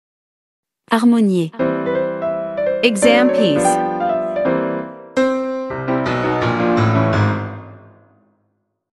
Professional-level Piano Exam Practice Materials.
• Vocal metronome and beats counting
• Master performance examples